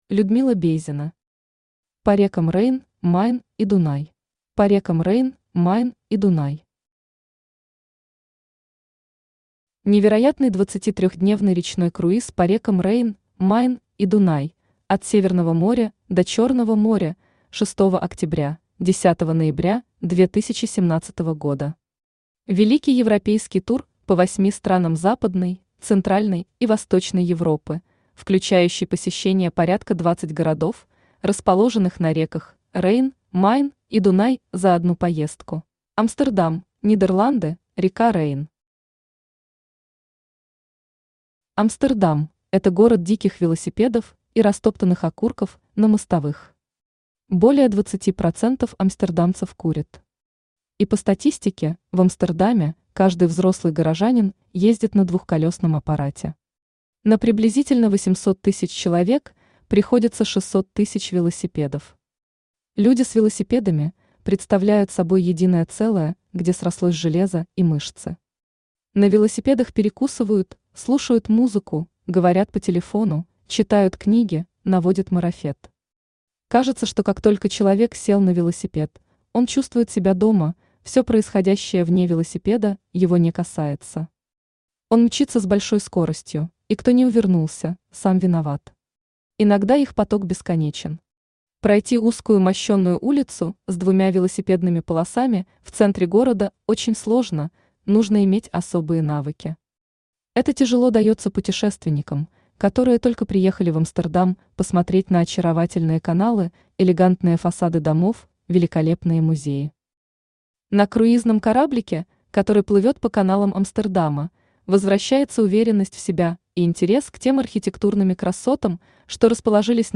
Аудиокнига По рекам Рейн, Майн и Дунай | Библиотека аудиокниг
Aудиокнига По рекам Рейн, Майн и Дунай Автор Людмила Георгиевна Бейзина Читает аудиокнигу Авточтец ЛитРес.